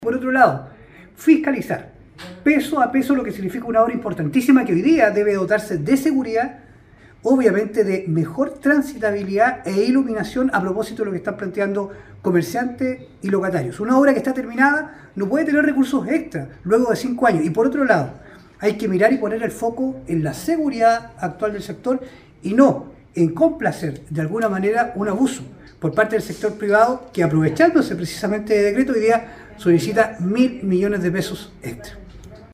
En la última sesión plenaria del Consejo Regional el core por la provincia de Osorno, Francisco Reyes Castro, dijo que fiscalizará cada peso extra que está solicitando la empresa ejecutora de la obra Avenida República, recepcionada con excesivo retraso el 2021, pese a que la importante vía está en marcha desde 2018, hace más de 5 años.